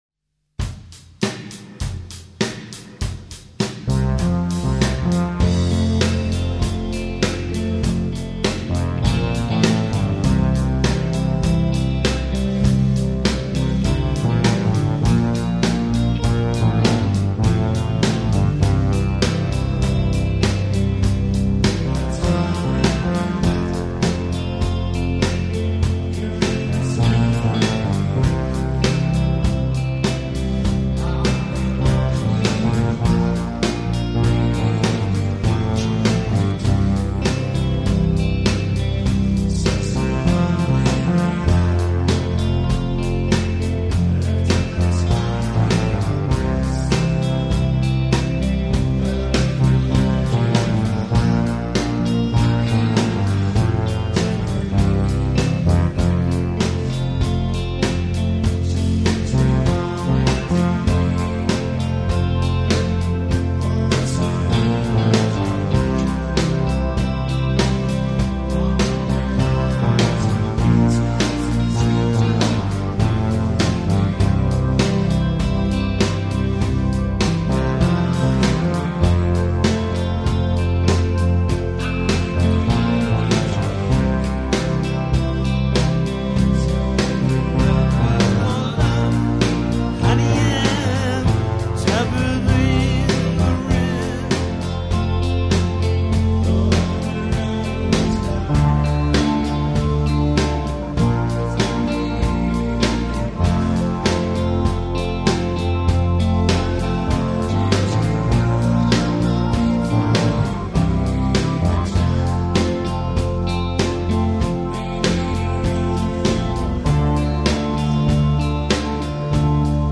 Aufnahmen aus dem Proberaum von 2016
Achtung: Bitte nur Stereo hören, die Aufnahmen sind nicht mono-kompatibel!